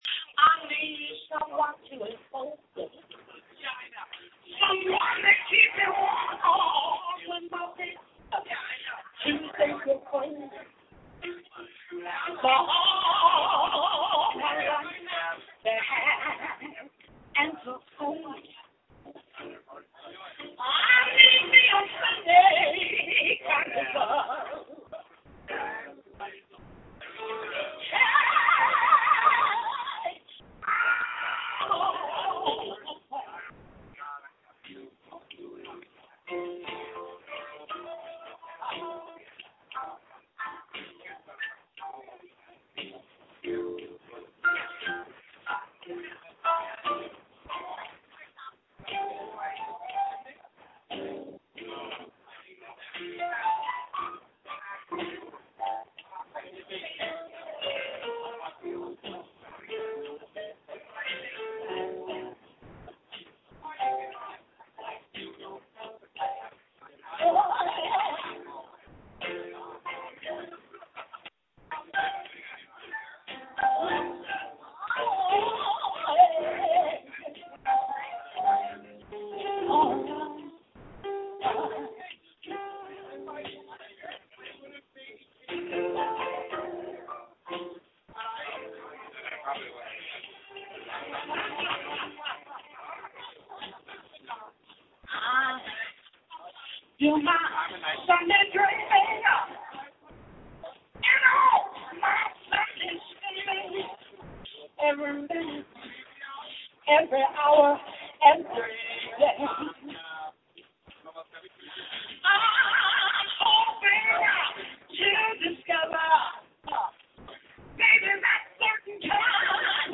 Sorry for the background noise. It's a bar.